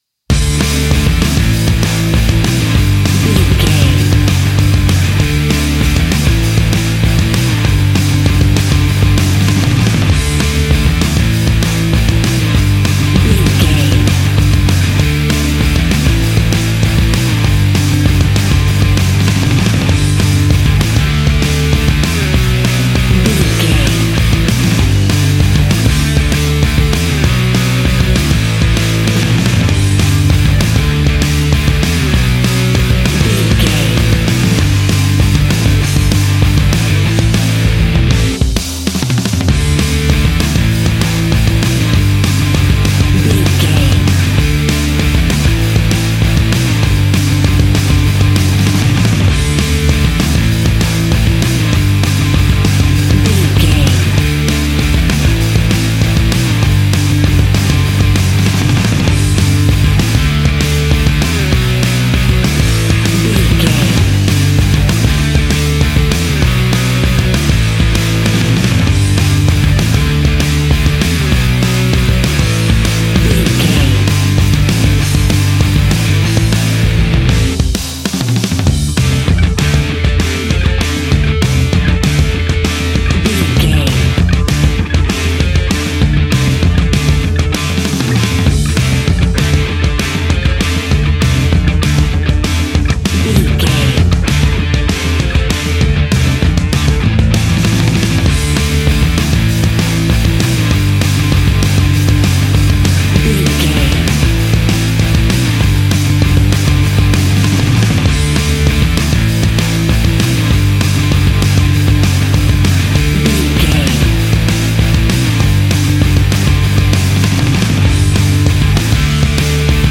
Uplifting
Fast paced
Ionian/Major
D
Fast
hard rock
distortion
punk metal
Rock Bass
Rock Drums
heavy drums
distorted guitars
hammond organ